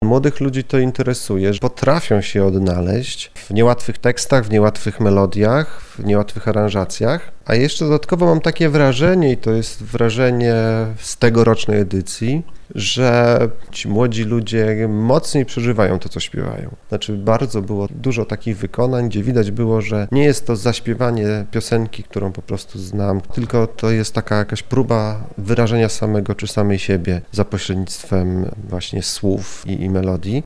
Koncert Galowy w Polskim Radiu Lublin zakończył tegoroczną 12. edycję konkursu poezji śpiewanej „Na strunach poezji”.